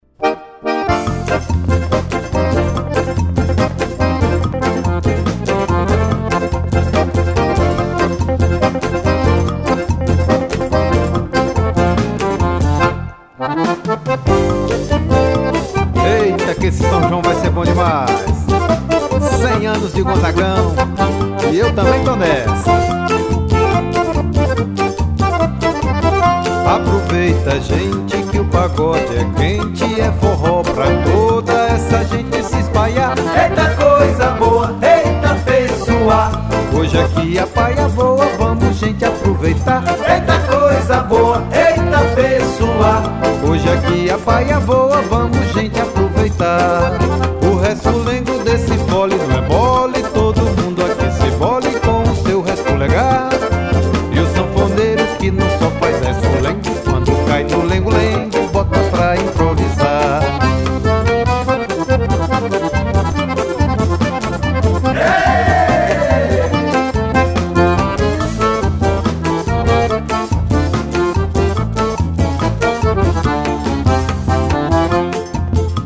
1060   01:15:00   Faixa:     Forró
Acoordeon
Triângulo
Guitarra
Baixo Elétrico 6
Cavaquinho
Bateria
Pandeiro, Zabumba